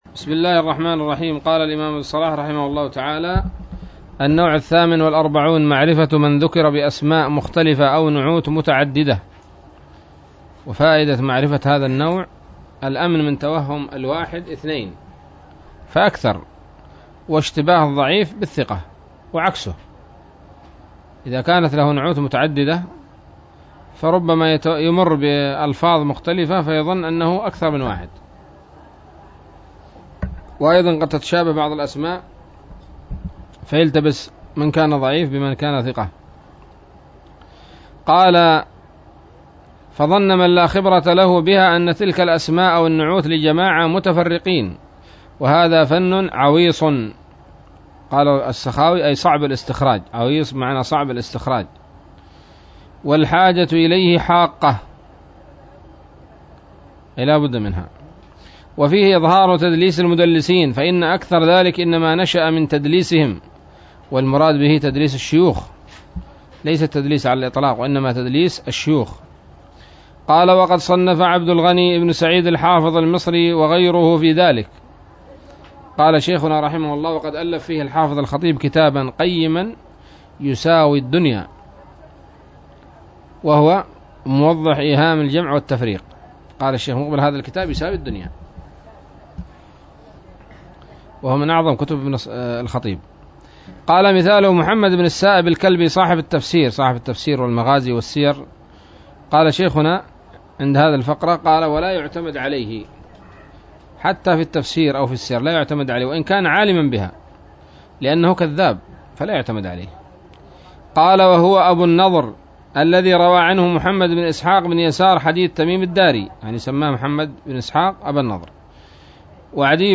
الدرس الخامس بعد المائة من مقدمة ابن الصلاح رحمه الله تعالى